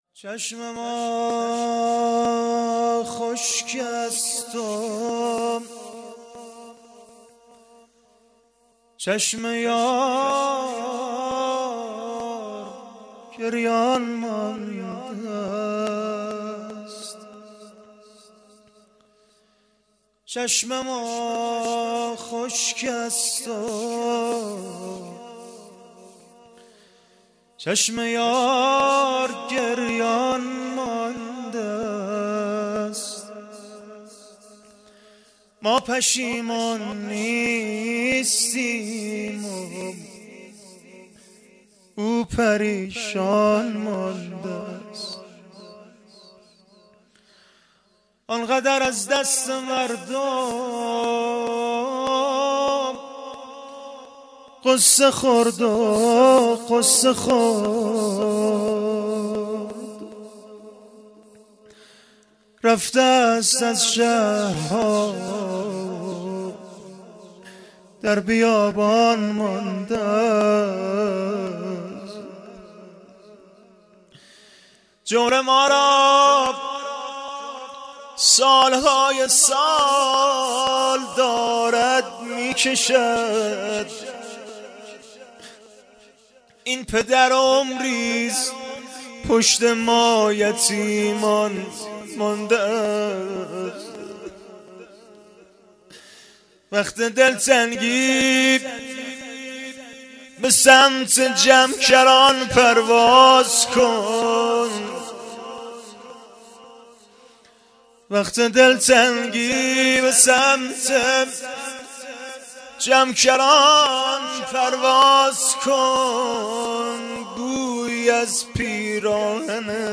روضه ورودی به کربلا
شب دوم محرم 1396
مراسم کلور اردبیل